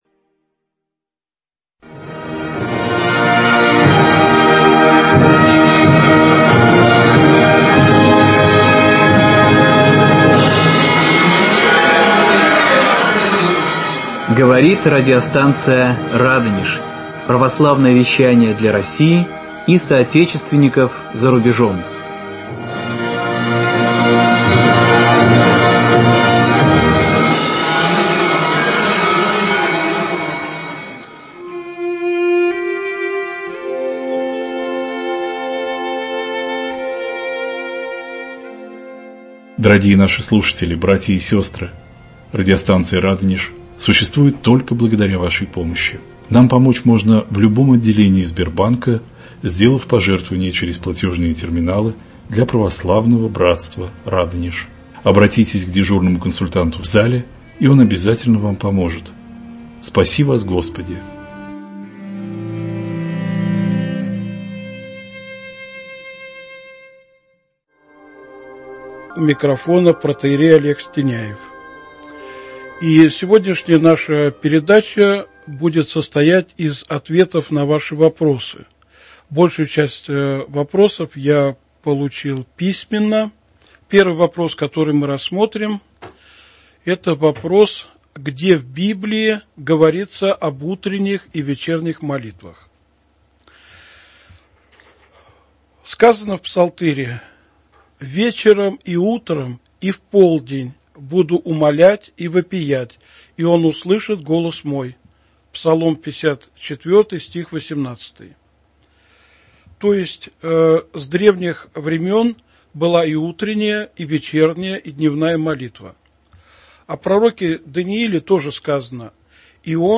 Ответы на вопросы радиослушателей.